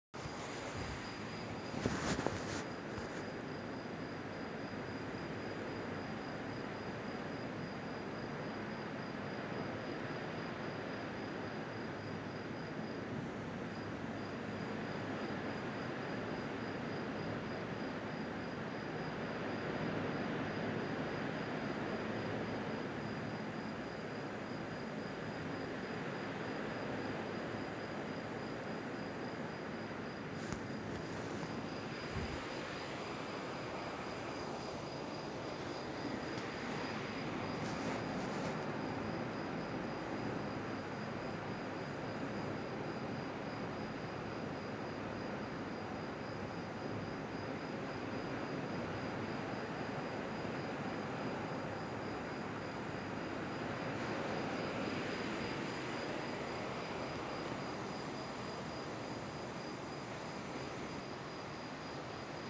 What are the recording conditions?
Dawn in Chania, Crete dawn_in_chania.mp3